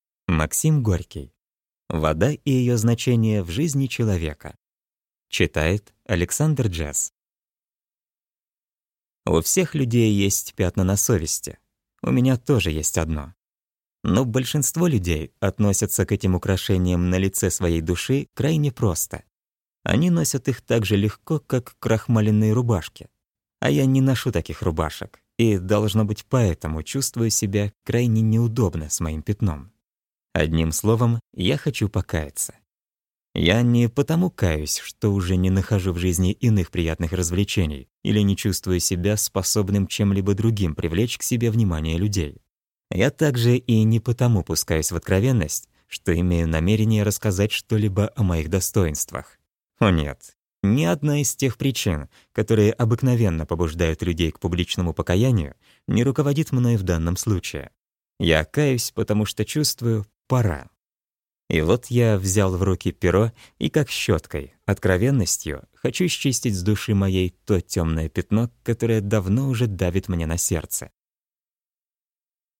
Аудиокнига Вода и ее значение в природе и жизни человека | Библиотека аудиокниг